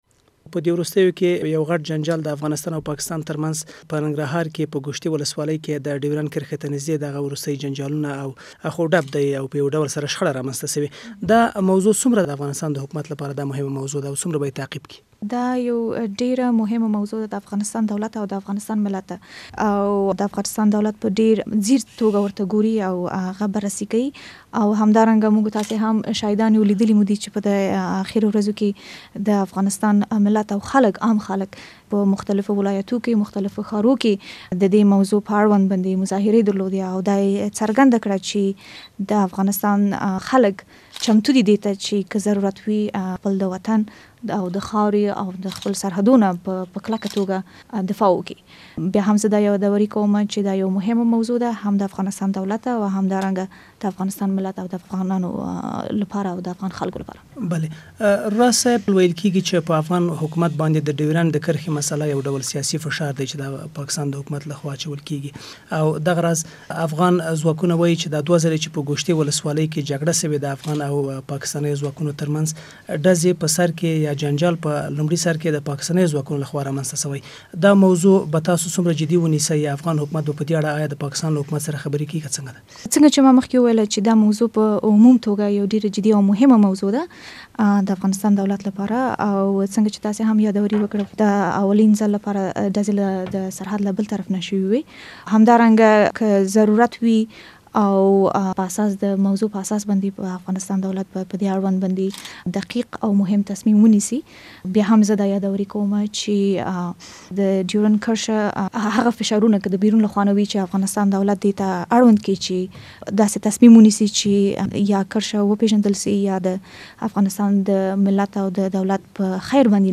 له عادلې راز سره مرکه